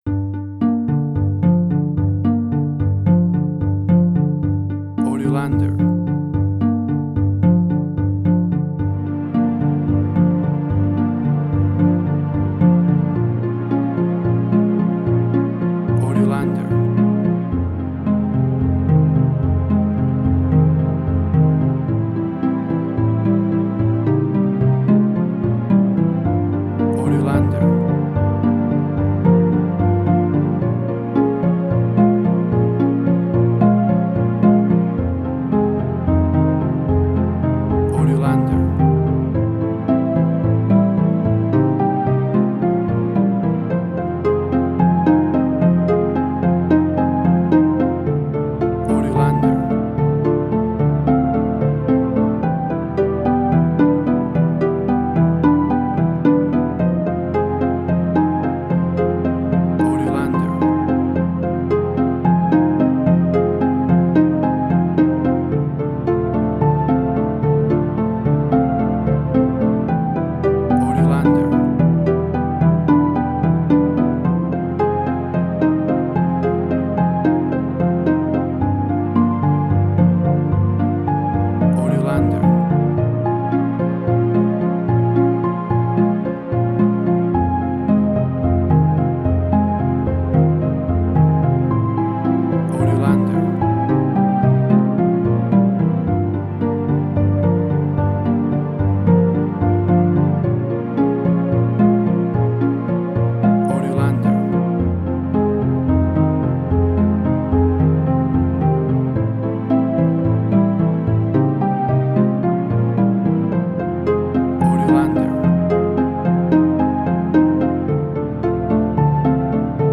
emotional music
Tempo (BPM): 110